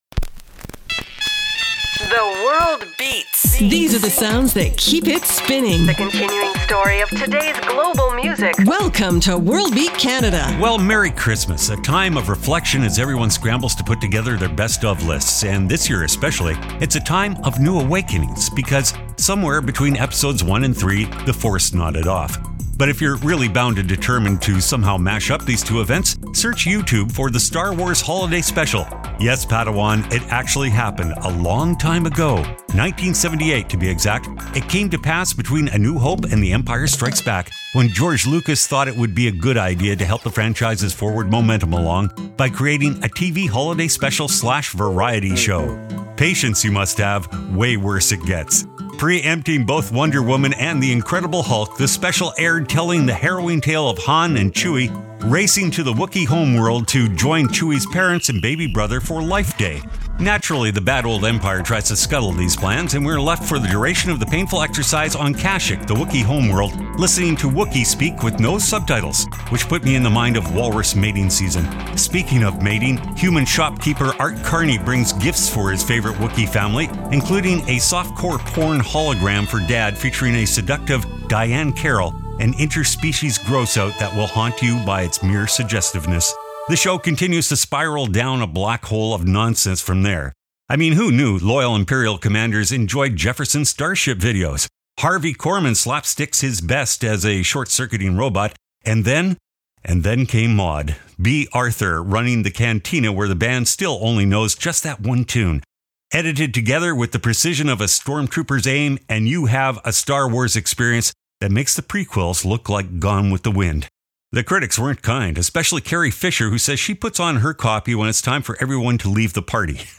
OUR YEAR END SPECIAL COUNTDOWN OF THE BEST GLOBAL GROOVES!
File Information Listen (h:mm:ss) 0:59:39 WBC_Radio_December_26_2015 Download (9) WBC_Radio_December_26_2015.mp3 71,595k 160kbps Stereo Comments: The best global grooves from the year that was 2015, pulled from the top of monthly worldbeat charts.